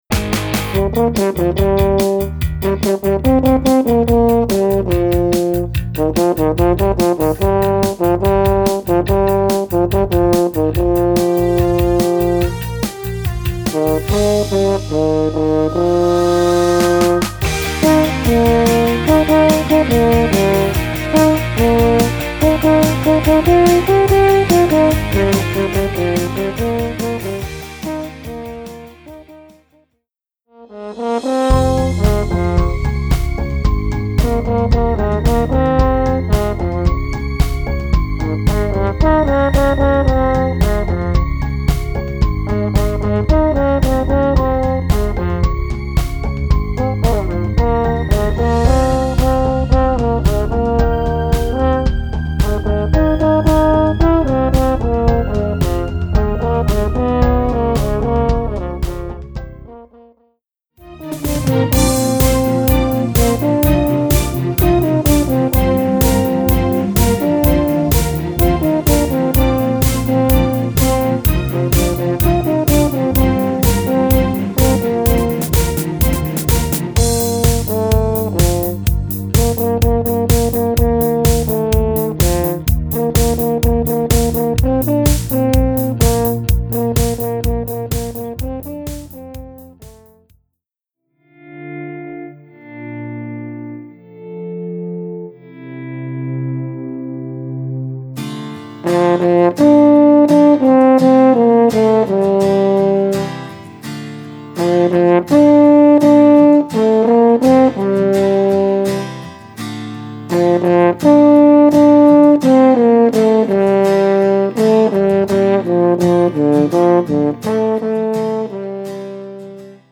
Voicing: French Horn w/c